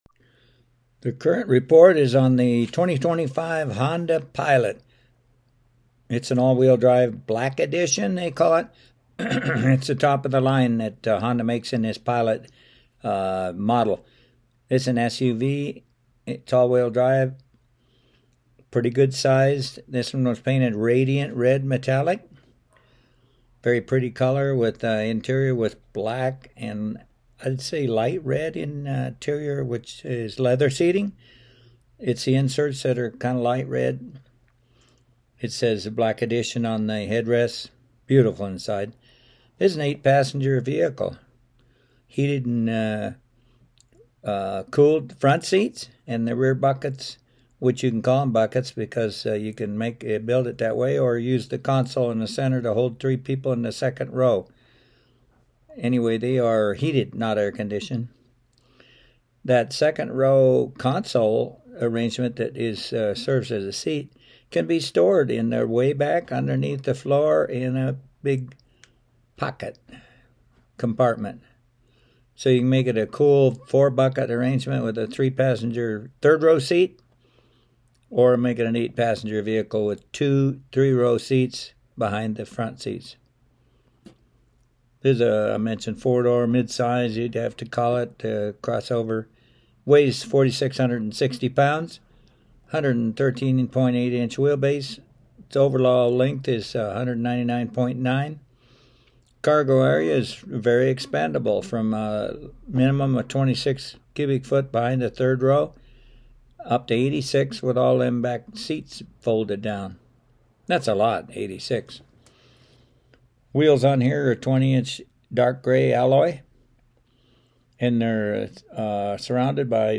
The Pilot was reviewed at Pirate Radio studios: